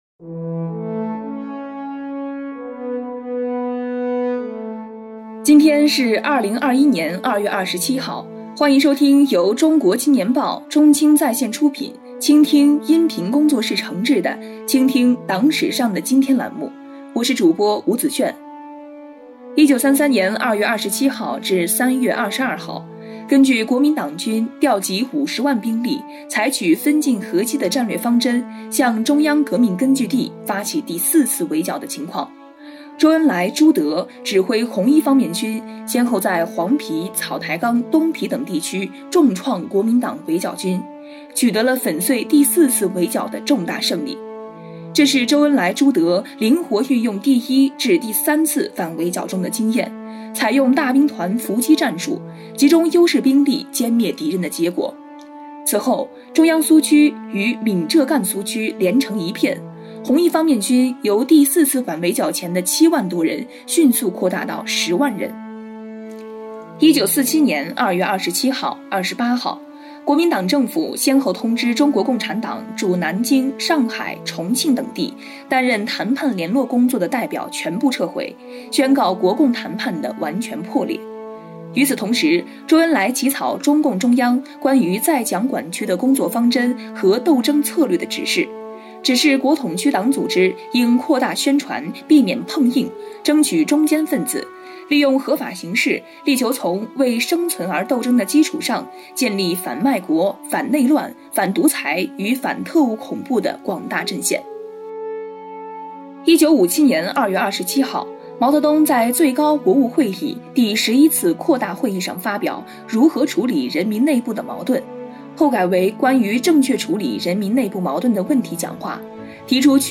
实习主播：